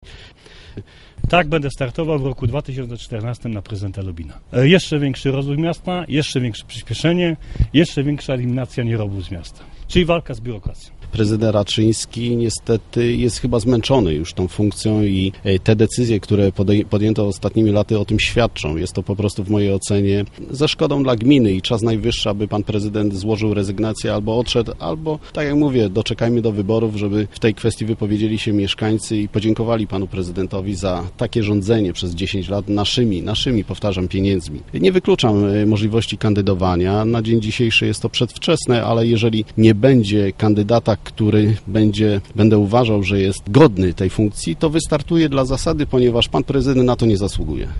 Prezydent Robert Raczyński, radny Krzysztof Olszowiak: